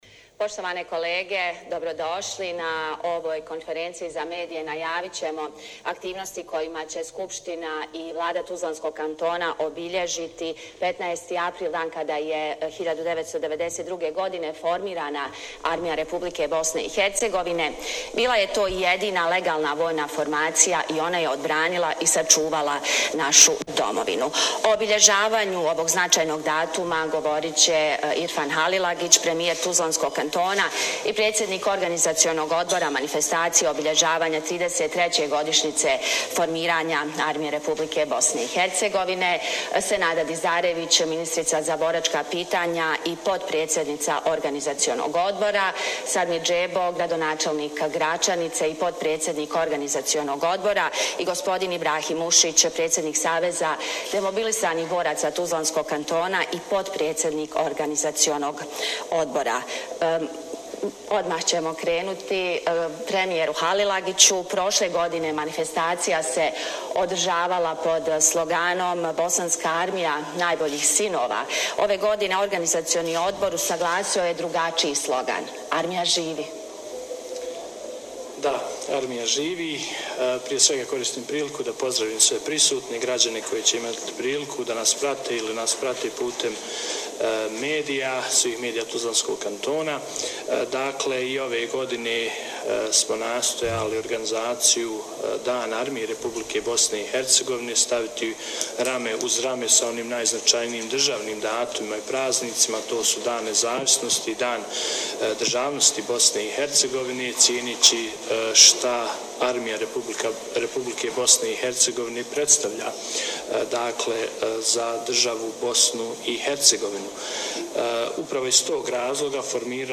Cijelu pres-konferenciju možete poslušati u nastavku